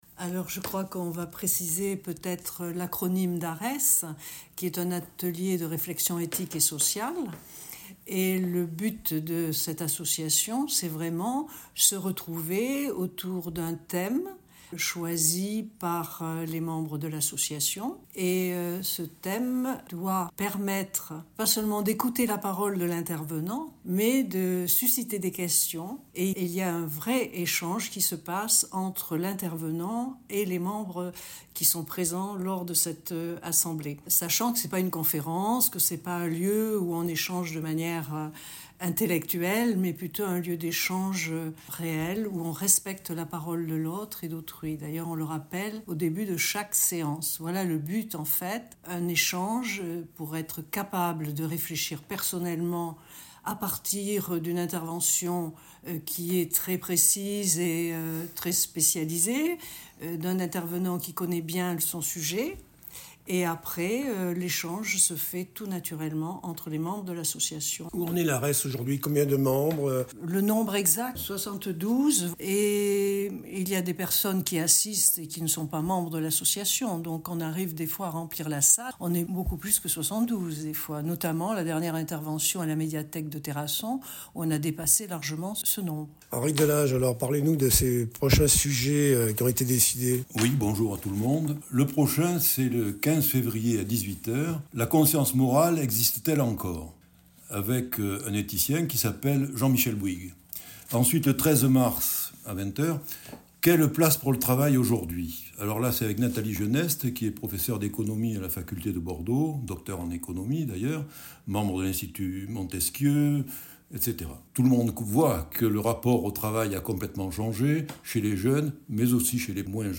– Interview audio…